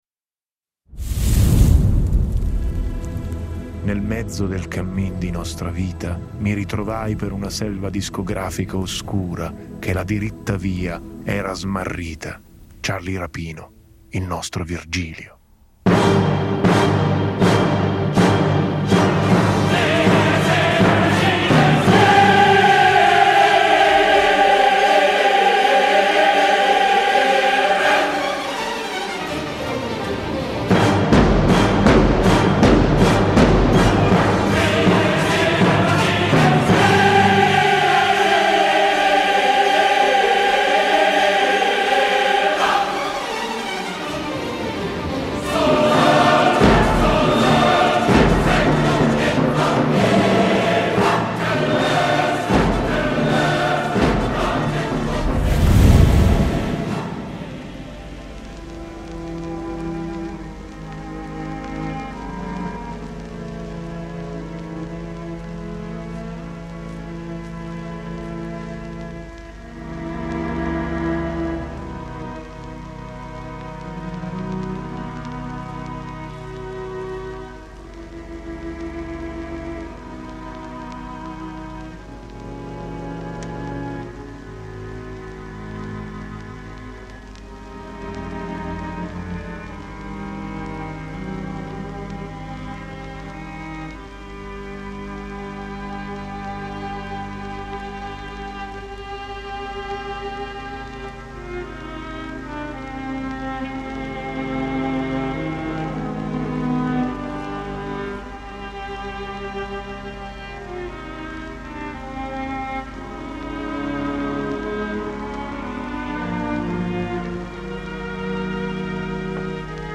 Per raccontare la storia della discografia, abbiamo immaginato una trasmissione radiofonica con cinque personaggi.